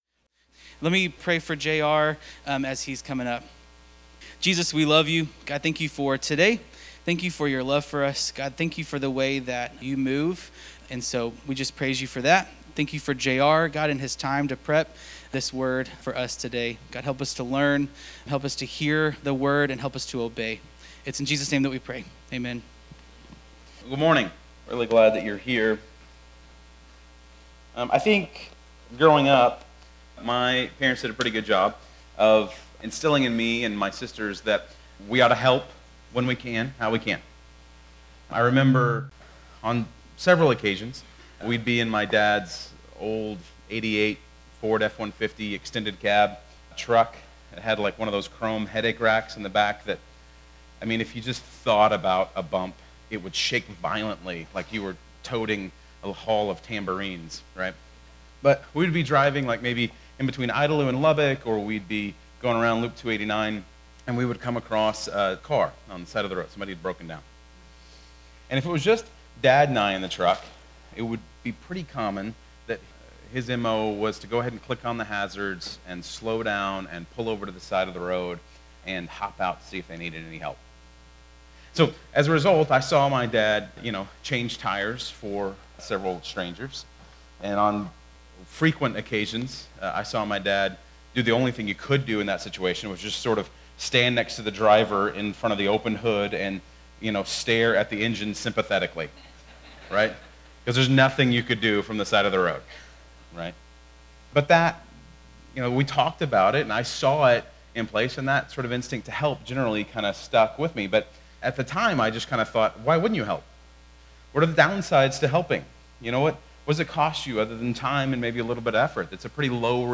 Bible Text: Philippians 2:1-11 | Preacher